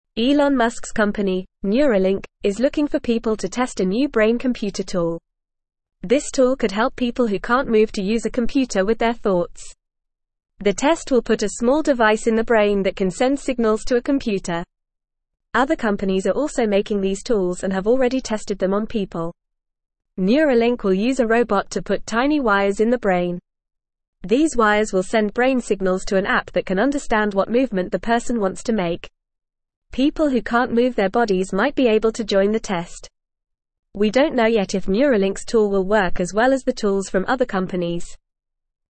Fast
English-Newsroom-Beginner-FAST-Reading-New-Brain-Tool-Help-People-Move-Computers-with-Thoughts.mp3